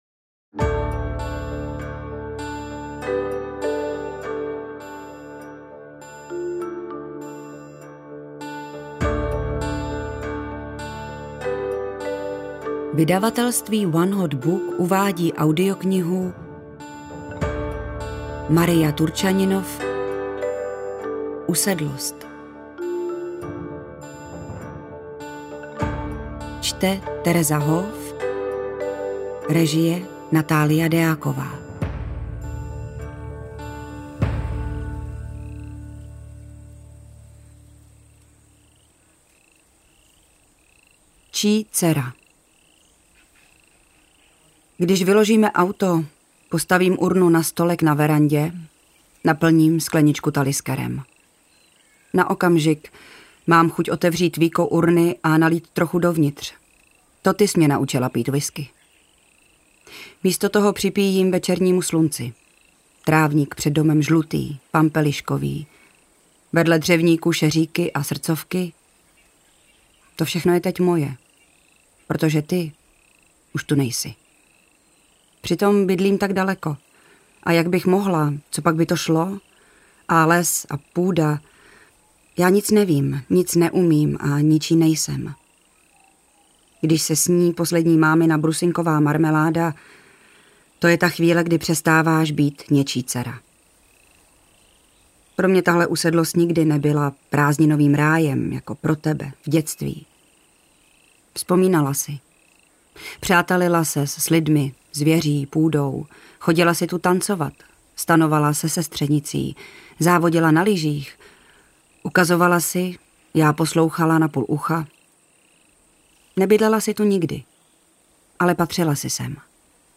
AudioKniha ke stažení, 32 x mp3, délka 11 hod. 28 min., velikost 626,0 MB, česky